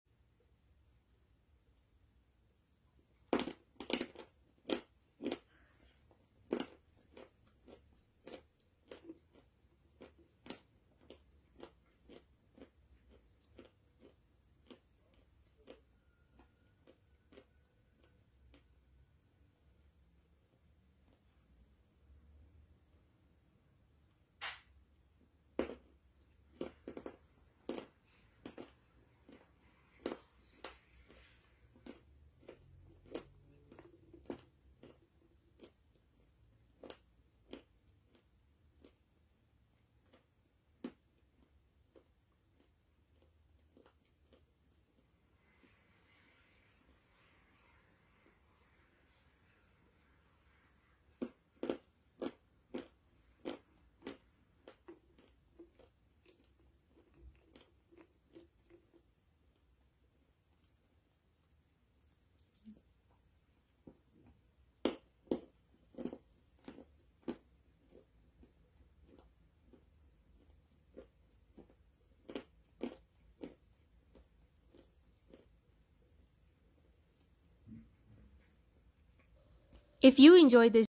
ASMR. kaolin crunchy dégustation. edible sound effects free download